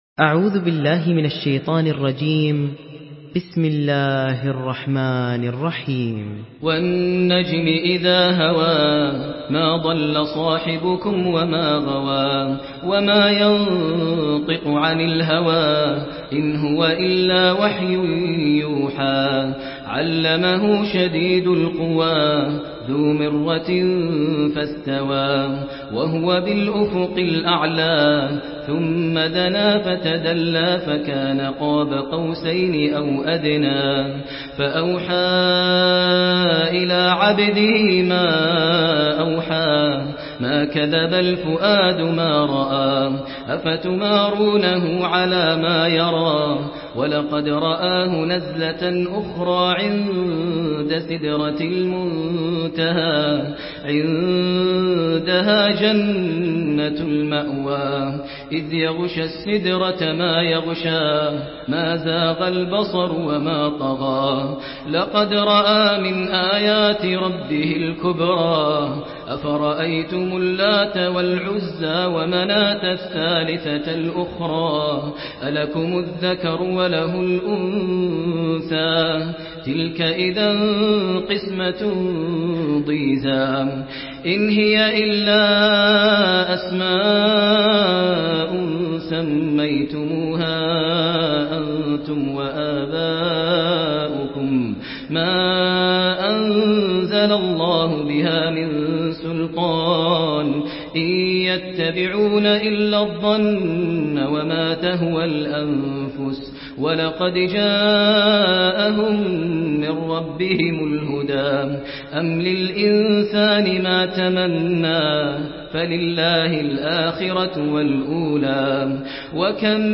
Surah An-Najm MP3 by Maher Al Muaiqly in Hafs An Asim narration.
Murattal Hafs An Asim